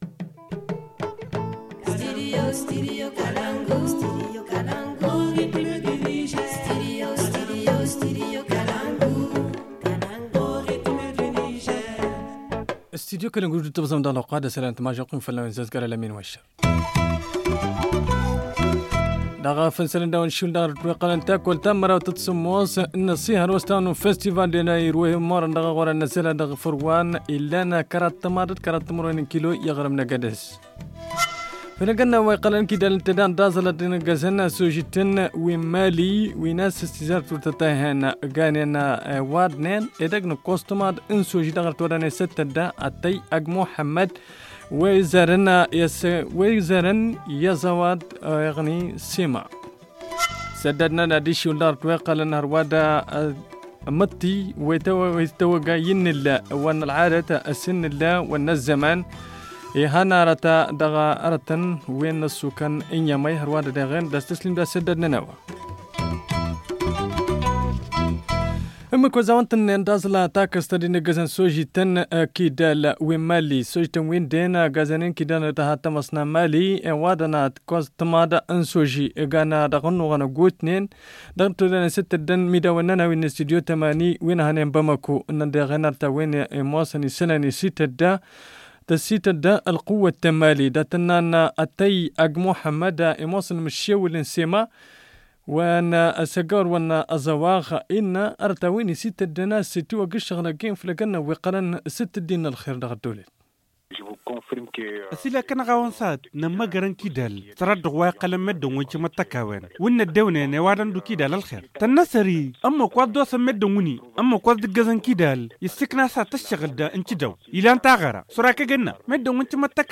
Le journal 14 février 2020 - Studio Kalangou - Au rythme du Niger